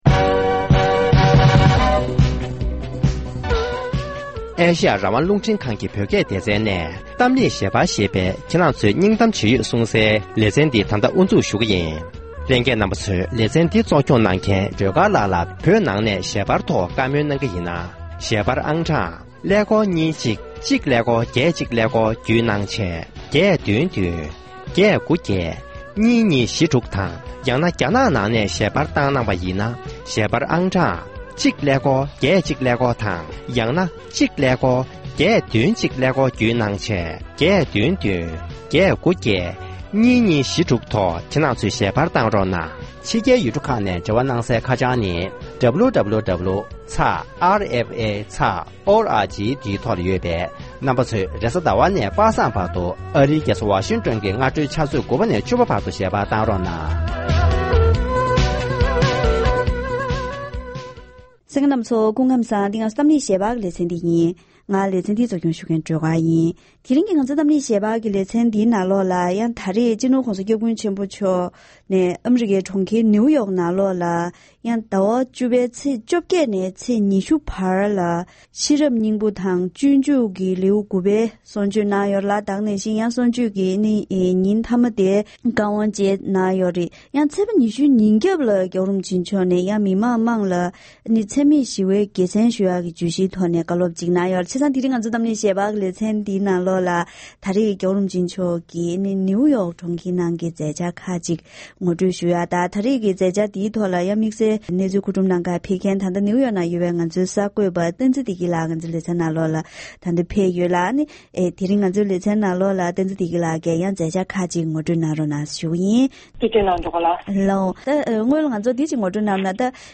ཤེས་རབ་སྙིང་པོའི་བཀའ་ཆོས།